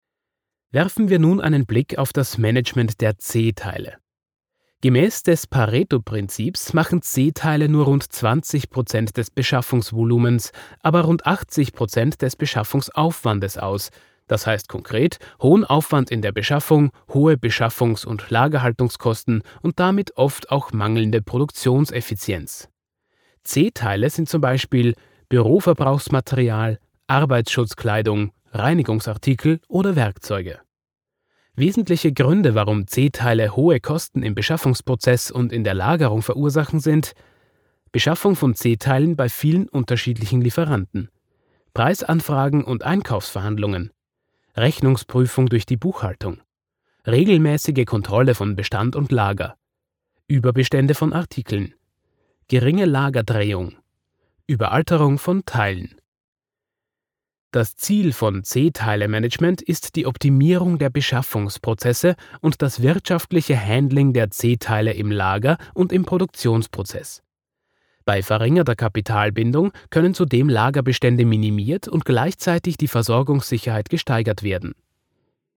E-Learning Sprecher - Eine klare Stimme für Ihre Lerninhalte
Egal ob ruhig und souverän für Finanz-E-Learnings oder jung und dynamisch zum Thema Guerilla Marketing – ich setze Ihr Schulungs oder E-Learning Projekt nach Ihren Wünschen um.